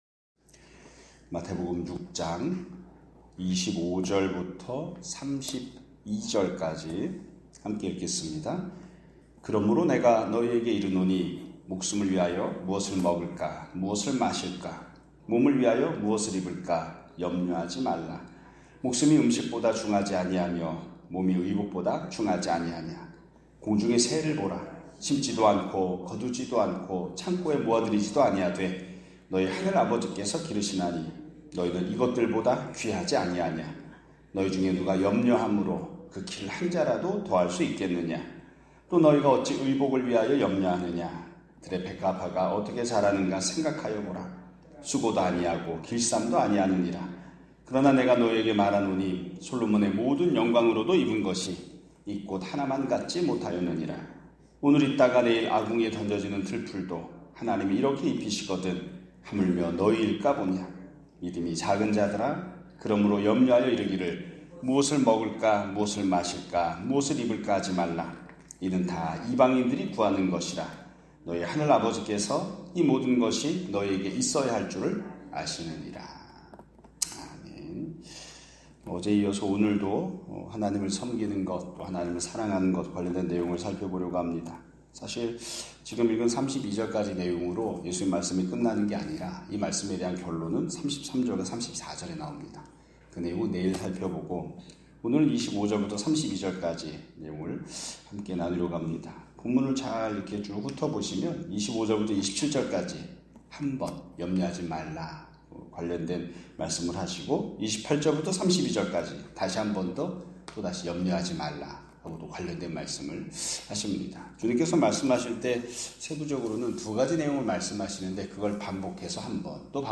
2025년 6월 19일(목요일) <아침예배> 설교입니다.